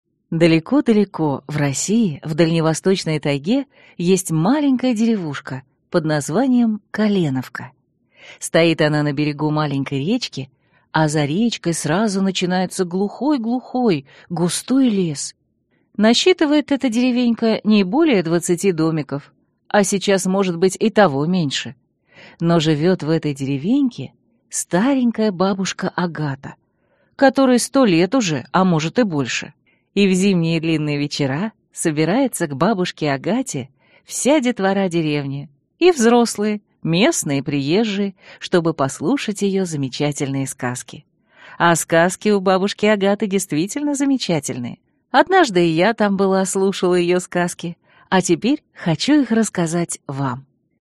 Аудиокнига Сказки мудрой бабушки Агаты | Библиотека аудиокниг